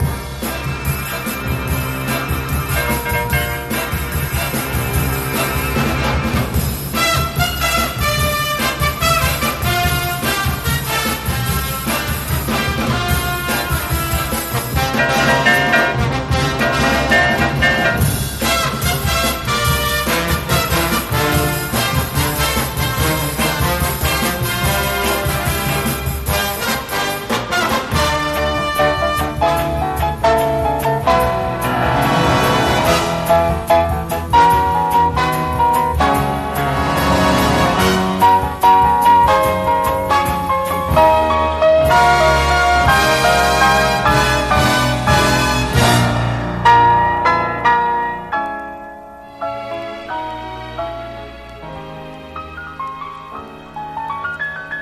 華やかな美麗オーケストラル・ピアノ・ラウンジ大傑作！
ジャズ、クラシック、ロック、カントリー、ブルースなどを横断し